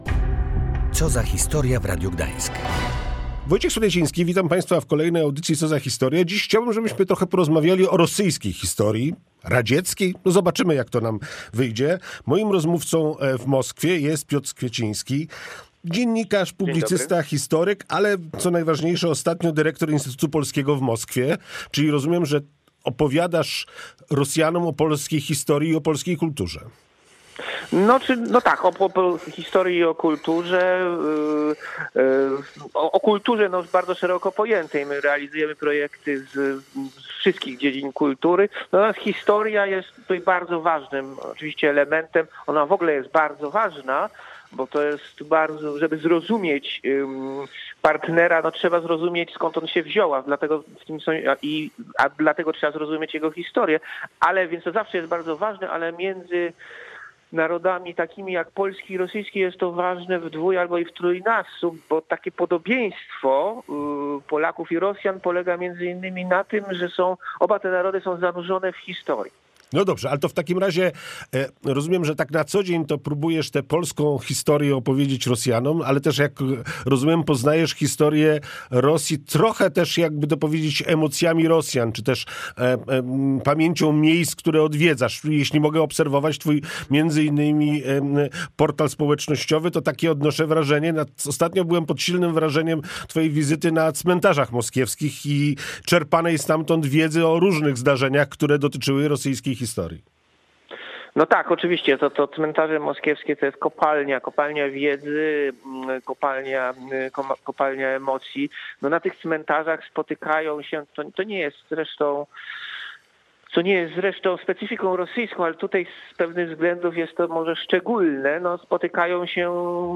Z tej okazji w audycji „Co za historia” rozmawiamy o tym, jak Rosjanie wspominają wydarzenia XX wieku. Dziedzictwo bolszewików i rewolucji nie zostało odrzucone w pamięci społecznej. Jak więc Rosja godzi w sobie wiele sprzecznych momentów w dziejach momentów?